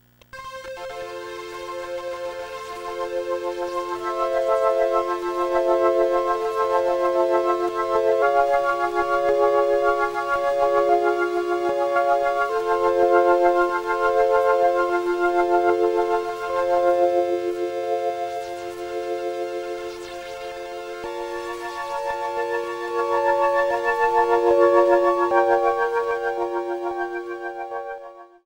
이 샘플은 메가 드라이브 게임